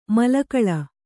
♪ malla kaḷa